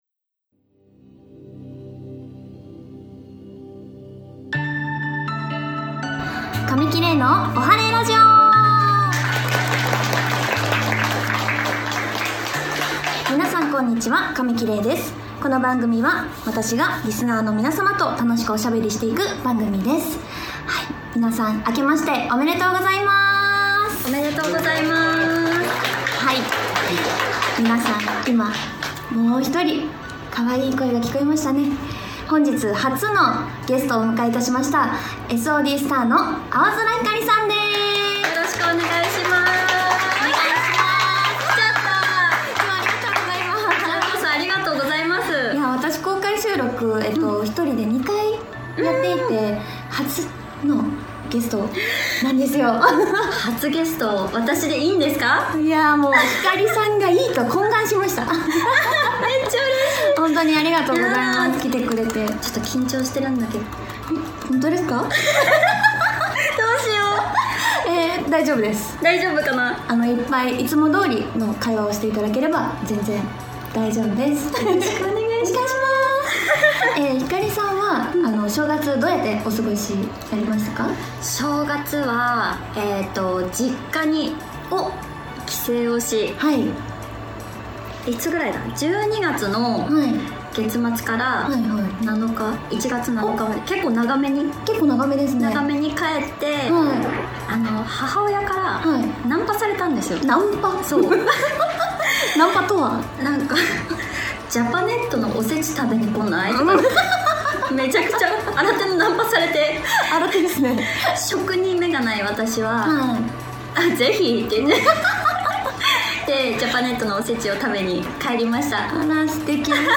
今年最初の配信は公開収録をお届けします！